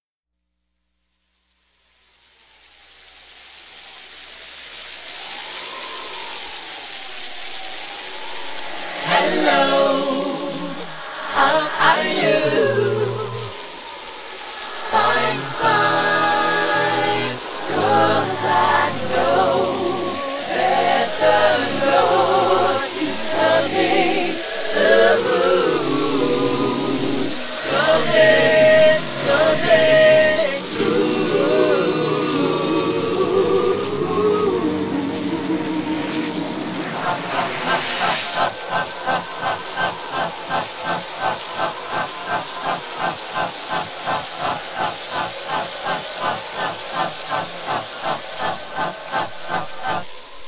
Este es el mismo fragmento, pero reproducido al revés.